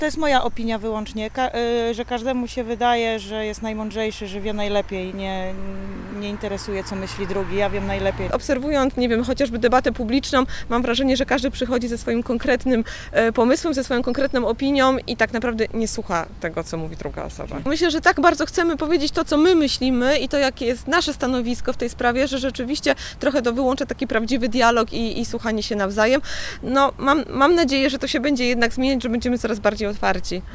Przy okazji Światowego Dnia Słuchania zapytaliśmy mieszkańców Wrocławia i osoby odwiedzające nasze miasto o opinię nt. tego, co może wpływać na zamykanie się na rozmowę z innym człowiekiem: